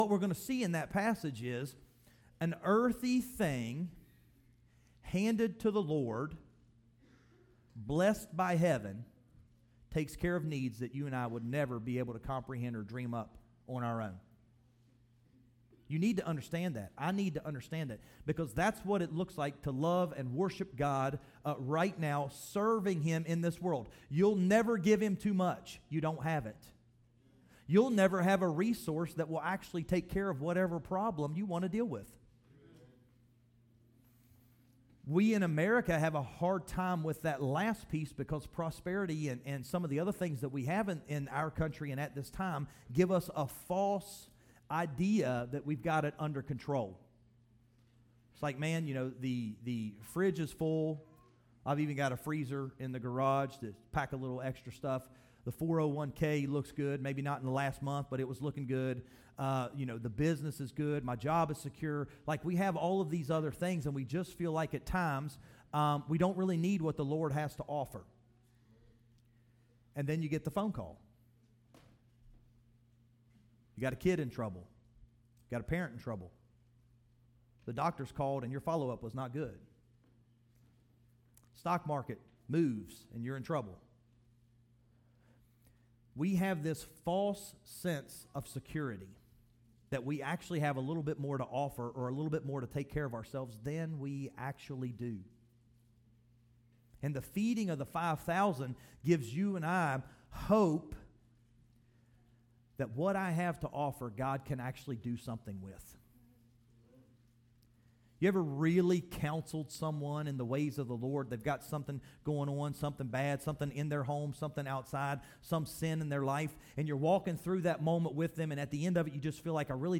Sermons by HBCWV … continue reading 280 episódios # Religion # Christianity # HBCWV